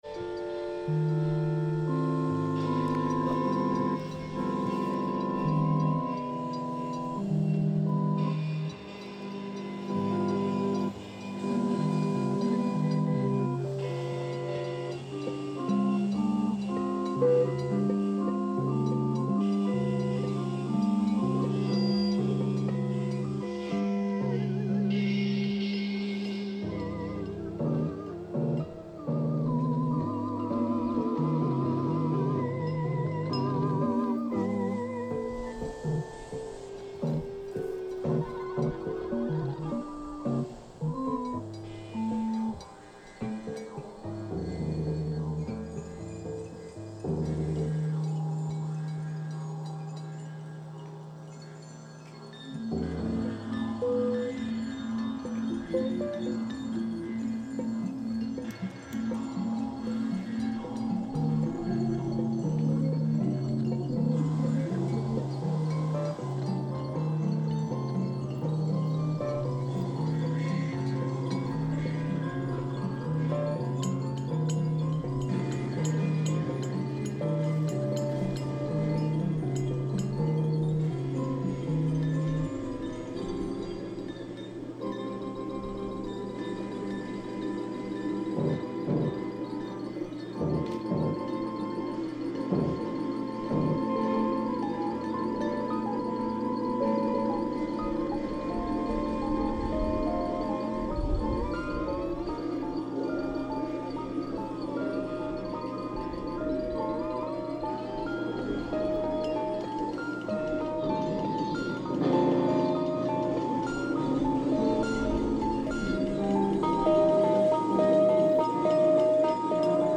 Live show from Salem Art Works in upstate New York...